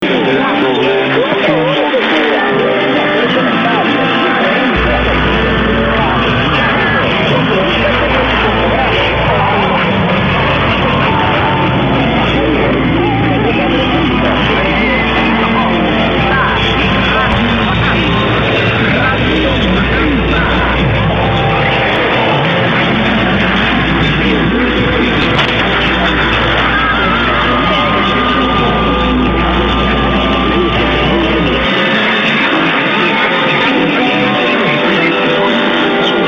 I have been quite surprised by the signal from this morning's 0400 file.